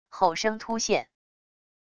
吼声突现wav音频